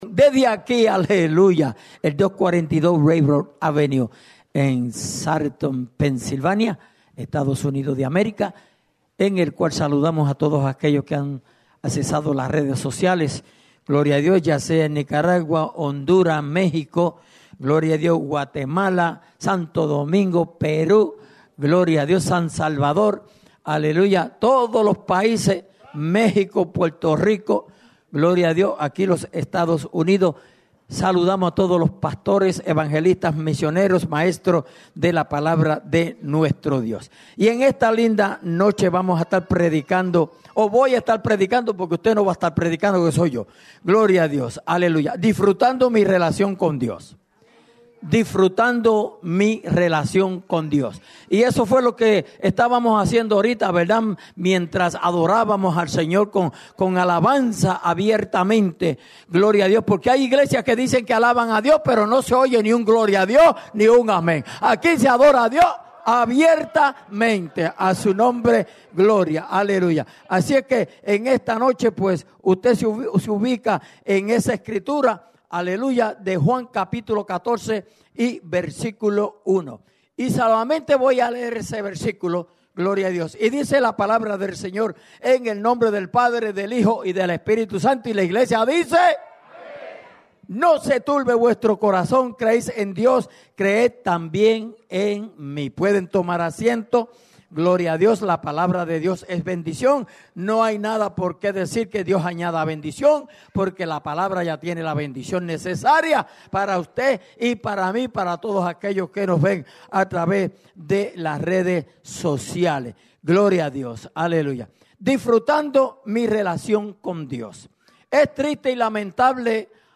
Segundo Mensaje Grabado @ Souderton